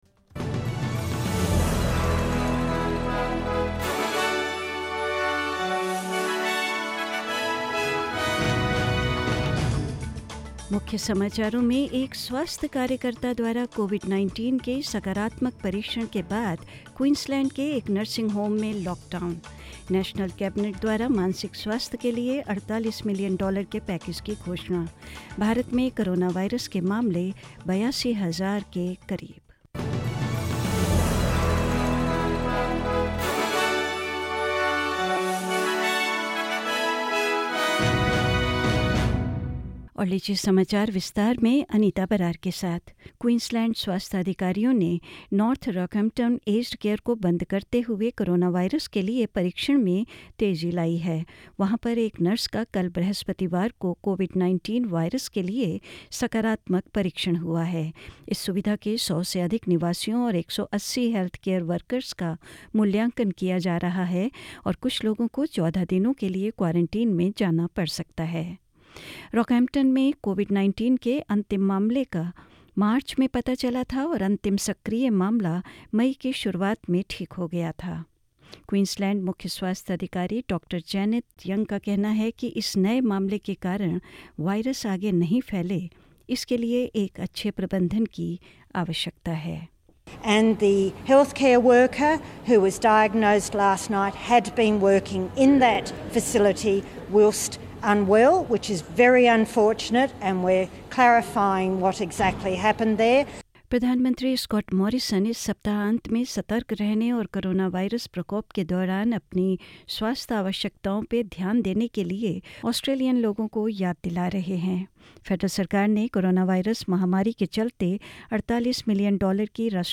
hindi_news_15th_may.mp3